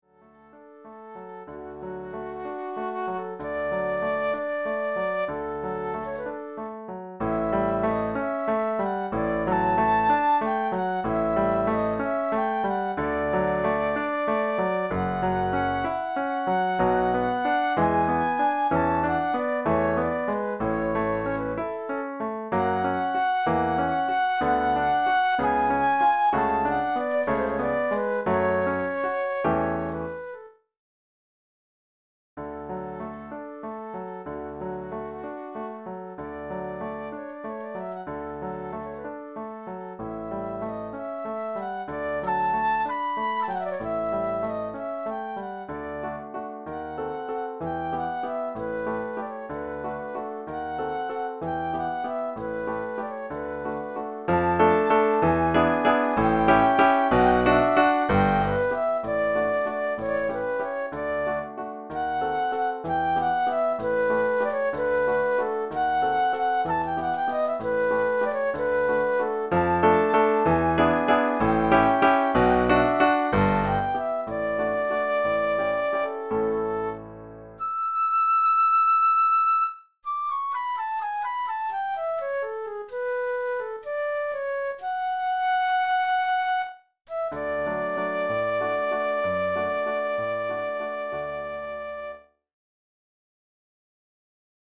Pour flûte et piano
(son numérique)